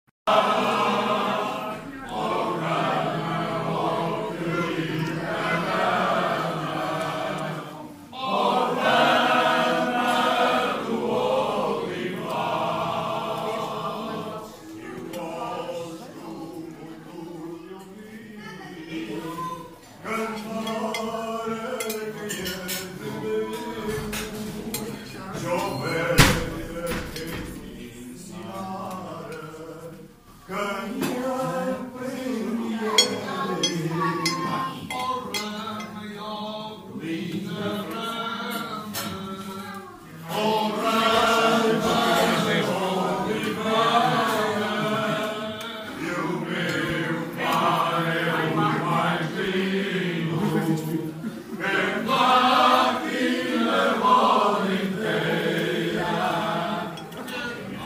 La sera in un’osteria a Mourão ci ritroviamo davanti ad un piatto povero a base di carne di maiale bollita, ceci conditi con olio e menta e un vino della casa, semplice e schietto, come la gente di qui, che tra un bicchiere e l’altro intona cori contadini per tutta la sera, cantando i lavori e i giorni, gli amori e i paesaggi.
voci-alentejo.mp3